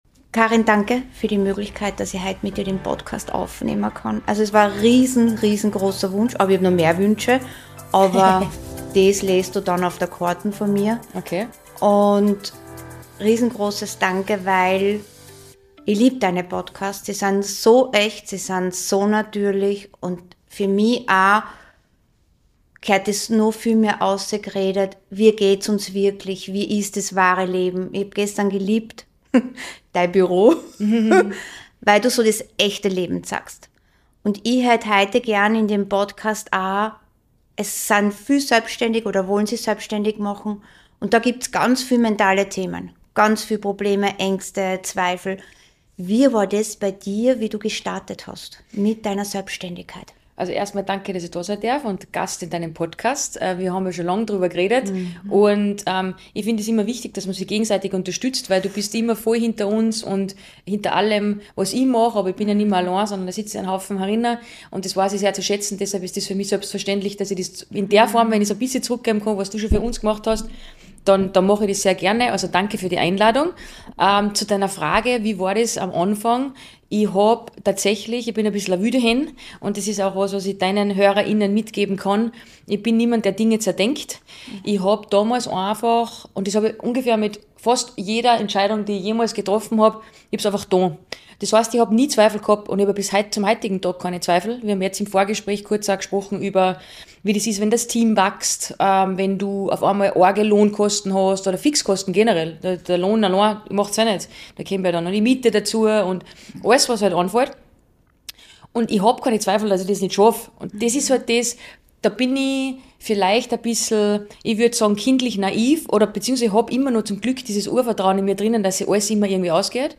Dieses Gespräch hat Tiefe und Ruhe zugleich.